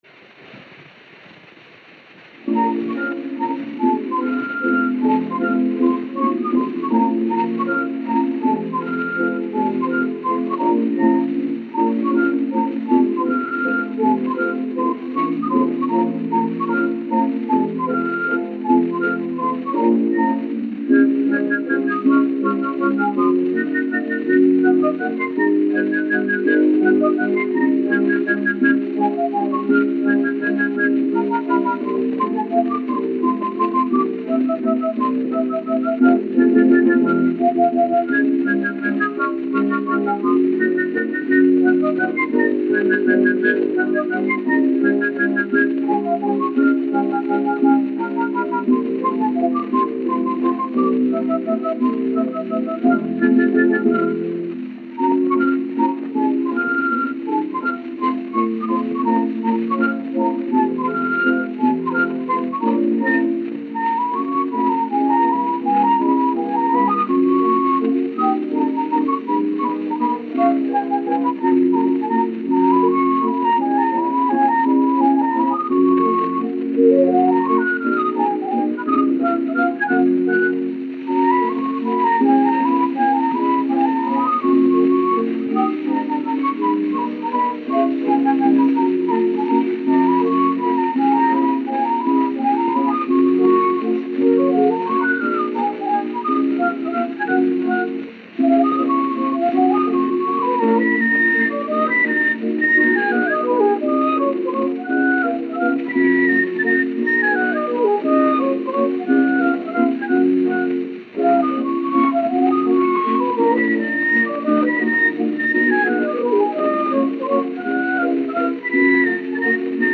Disco de 78 rotações, também chamado "78 rpm", gravado em apenas um dos lados e com rótulo "rosa".
O gênero musical foi descrito como "Mazurka".
Consta no rótulo a informação "Choro por Flauta Solo".
Gênero: Mazurka.